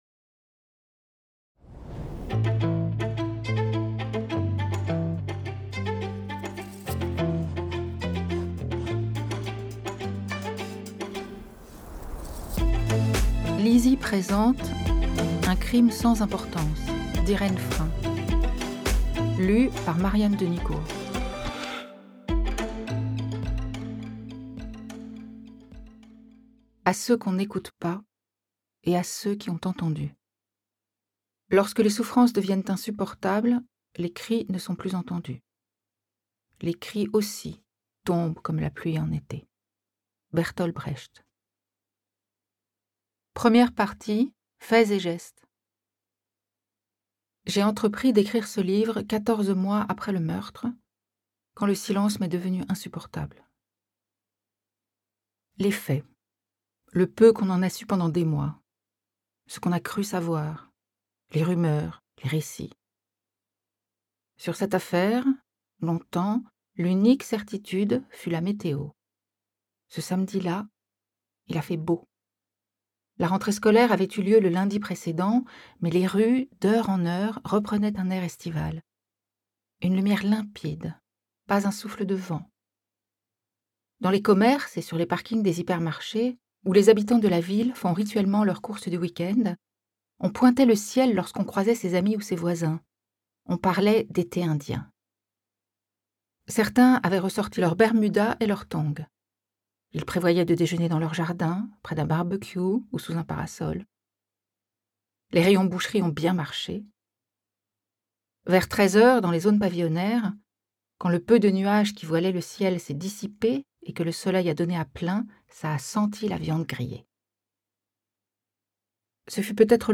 Extrait gratuit - Un crime sans importance de Irène Frain